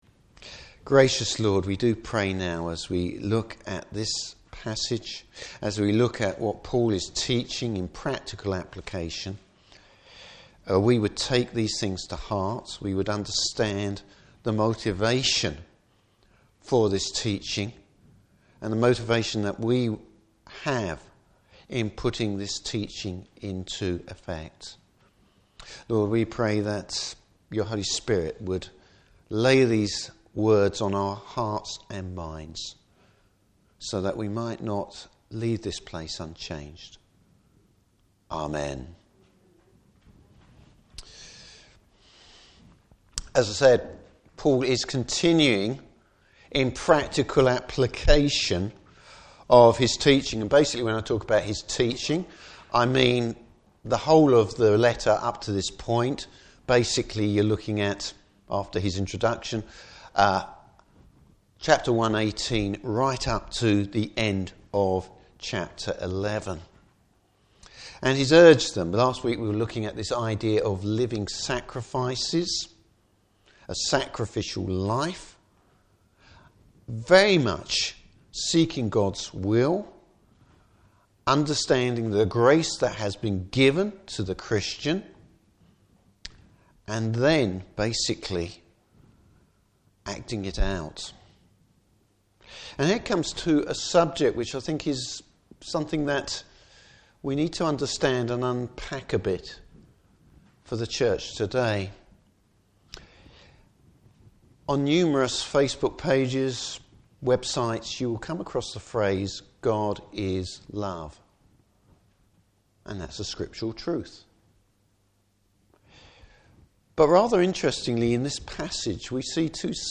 Service Type: Morning Service How love should motivate the Christian’s lifestyle.